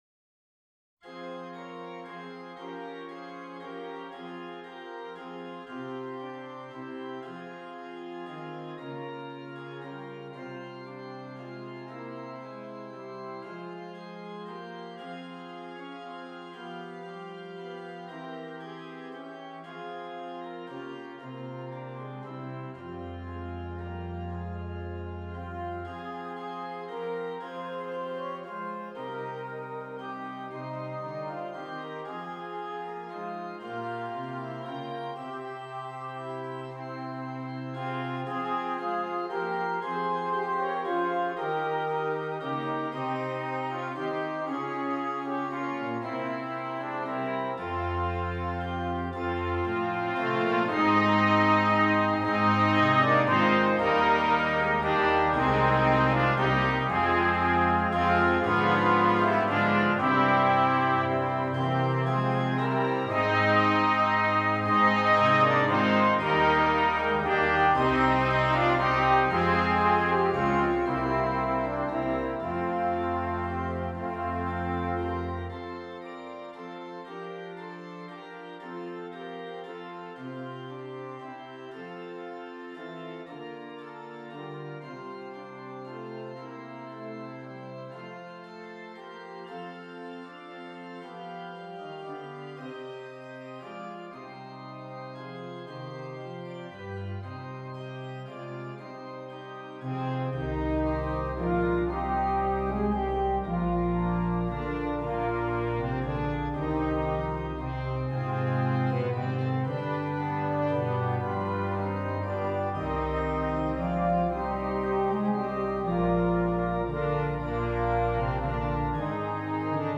Christmas
Brass Quintet and Organ